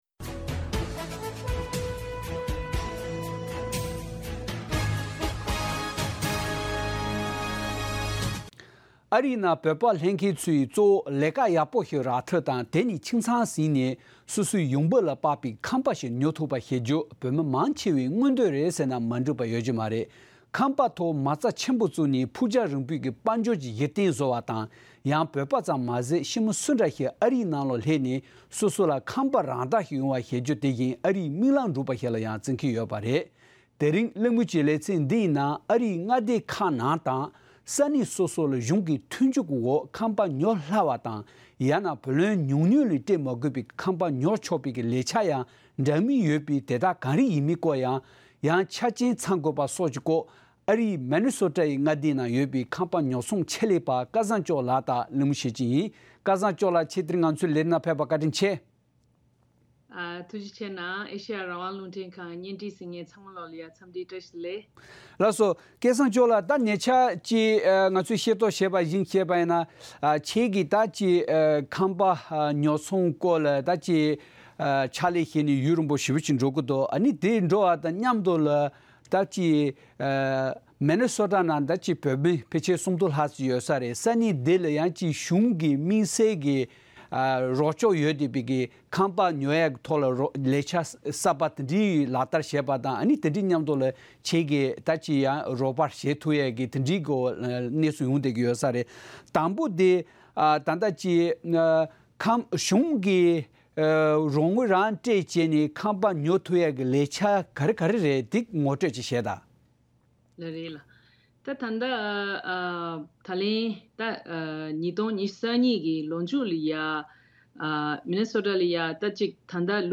གླེང་མོལ་གྱི་ལེ་ཚན་ནང་ཨ་རིའི་ནང་ཁང་པ་ཉོ་སྟངས་དང་མངའ་སྡེ་དང་གཞུང་གི་ལས་འཆར་གྱི་ཁེ་ཕན་ལེན་སྟངས་སྐོར་གླེང་པ།